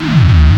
描述：信息 经过长时间的尝试和思考得出许多有趣的工具和结论 在具有良好记录质量的任何声音的足够长的处理中实现效果。
标签： 效应 外汇 SFX 科幻 SoundFX 声音
声道立体声